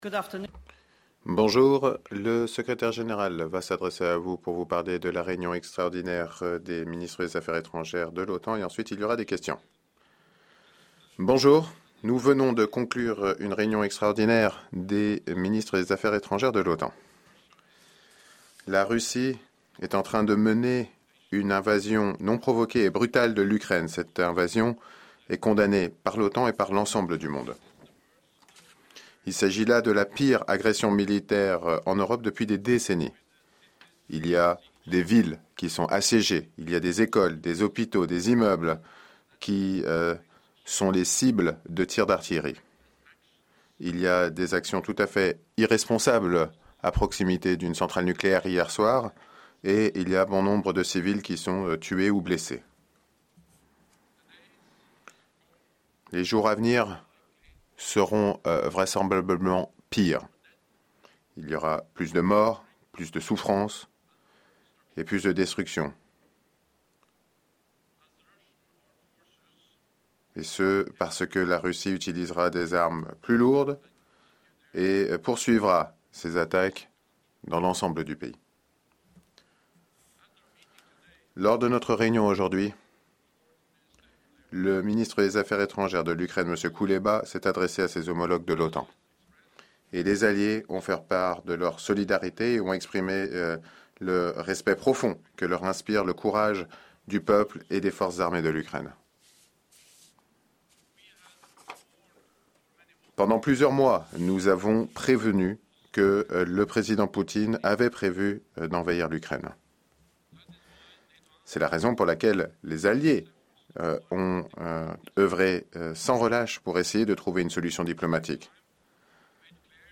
Press conference
by NATO Secretary General Jens Stoltenberg following the Extraordinary meeting of NATO Ministers of Foreign Affairs